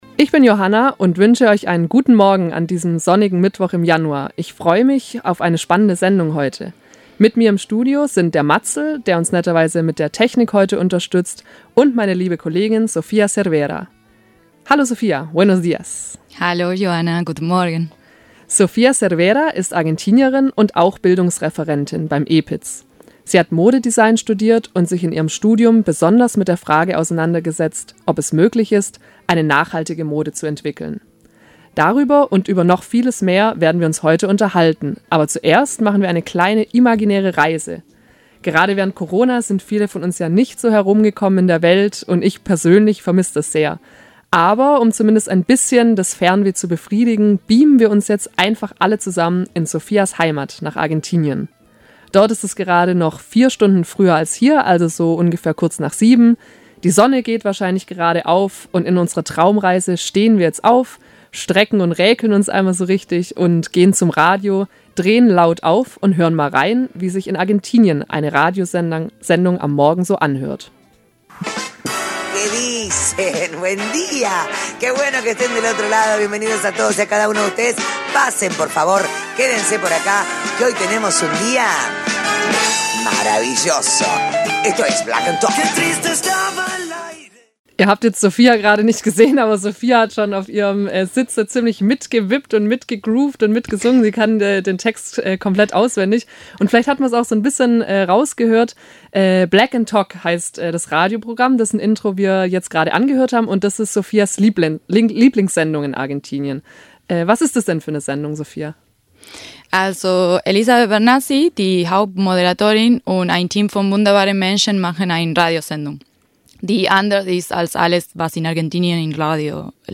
Mit guter Laune, guter Musik aus Spanisch/Deutsch und netter Gesellschaft machen sie eine Radiosendung über persönliche Erfahrungen und schöne Erlebnisse.